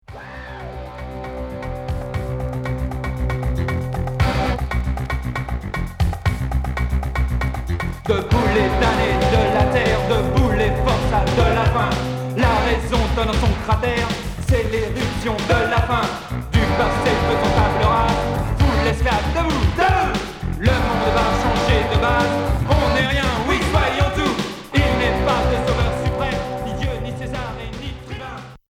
Alternatif